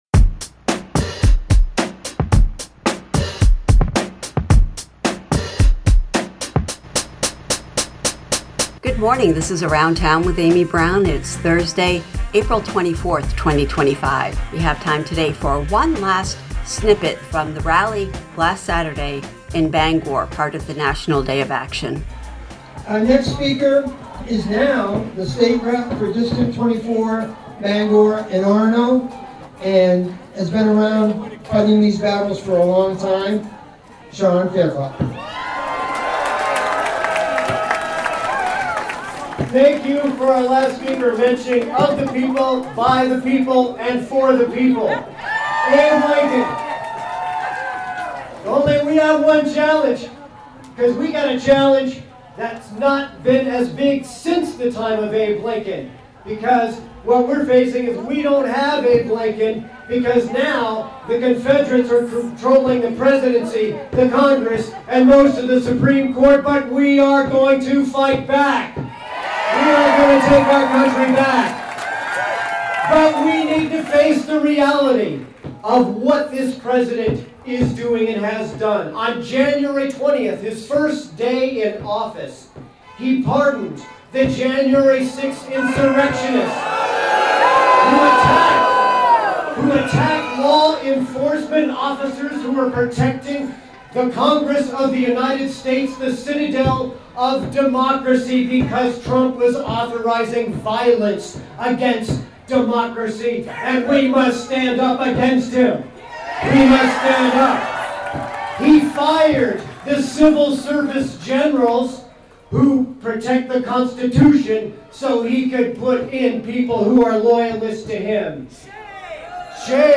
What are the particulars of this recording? One last clip from last Saturday’s rally at the Federal Building in Bangor – part of the national day of action